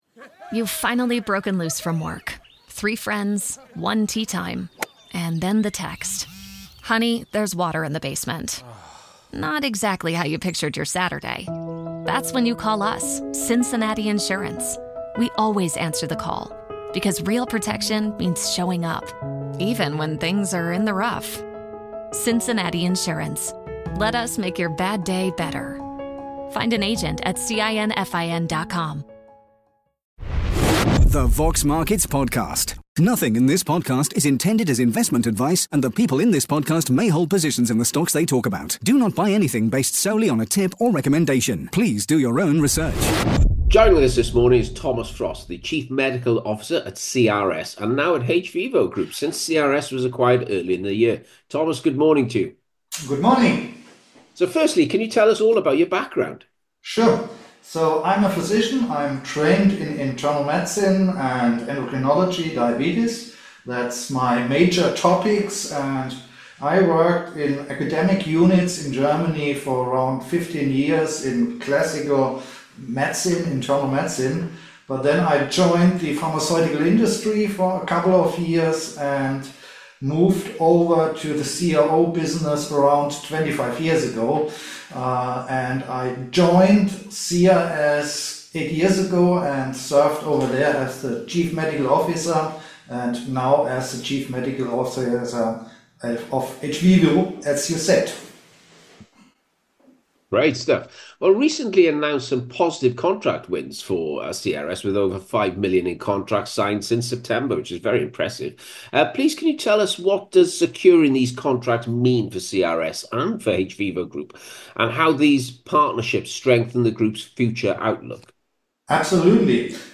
In this in-depth interview